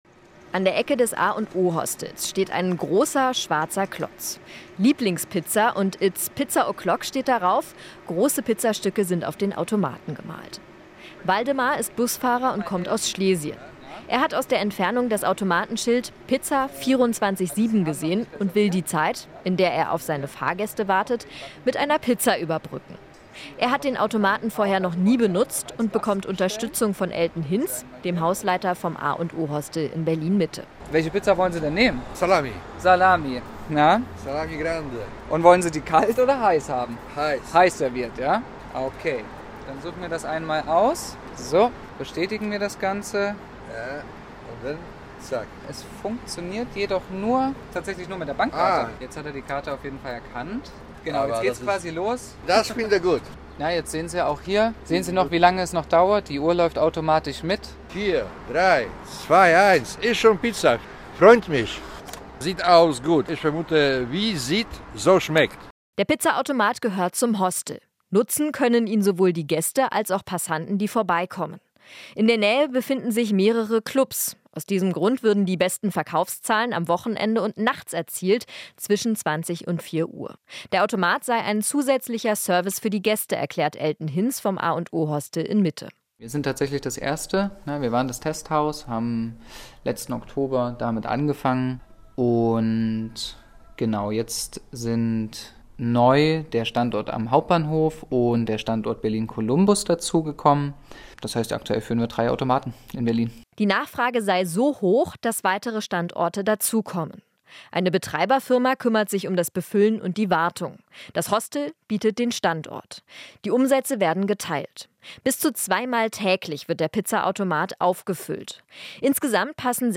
Ein Pizza-Automat steht vor einem Berliner Hostel